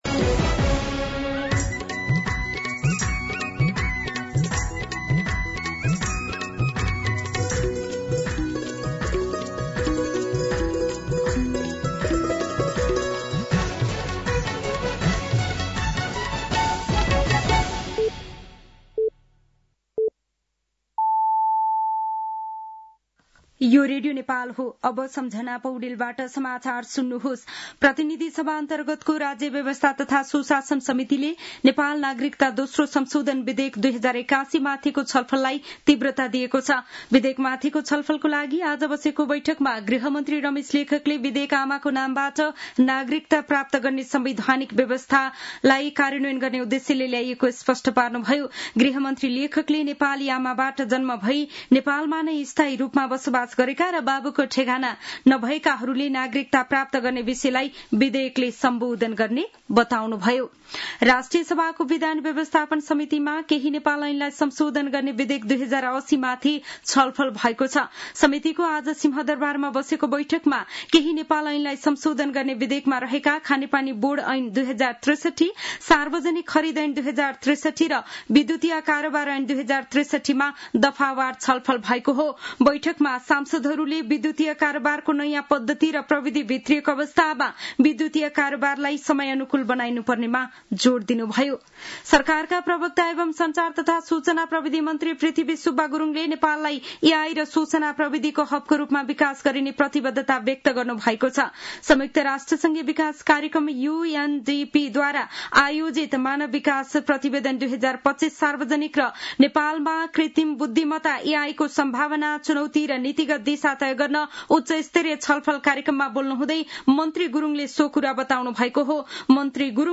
साँझ ५ बजेको नेपाली समाचार : २७ जेठ , २०८२